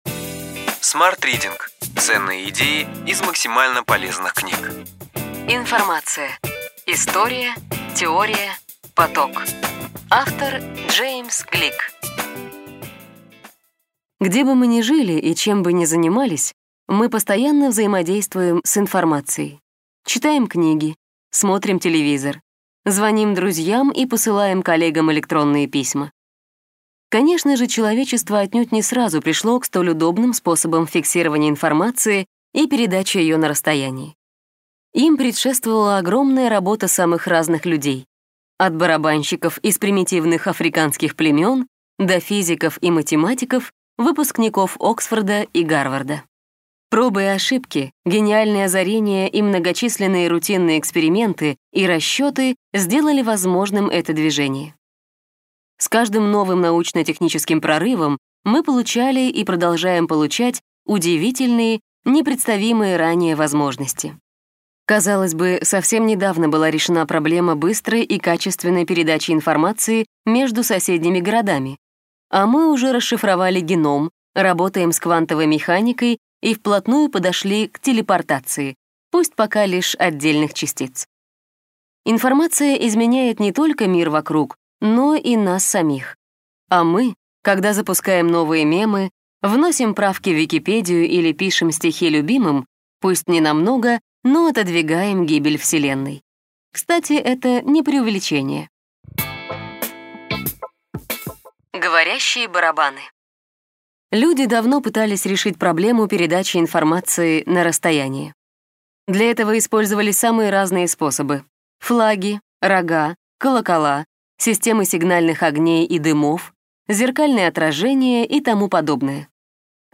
Аудиокнига Ключевые идеи книги: Информация. История. Теория. Поток. Джеймс Глик | Библиотека аудиокниг